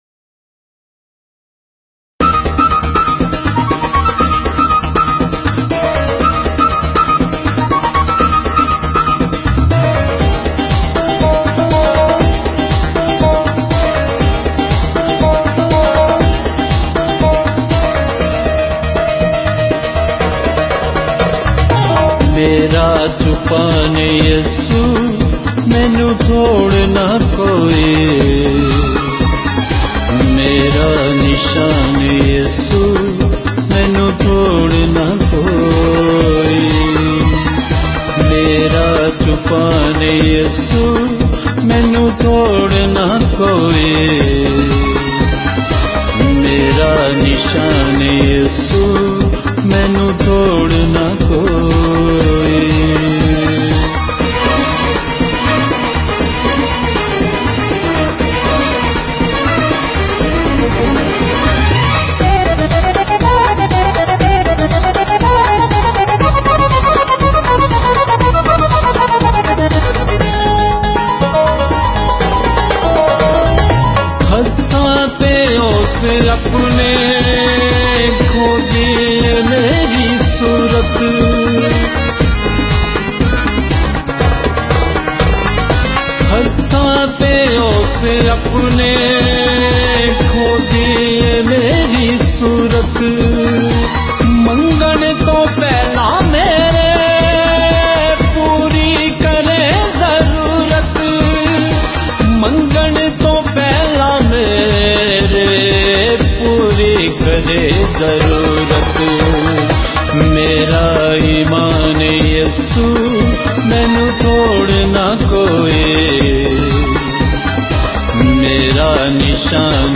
Listen and download Gospel songs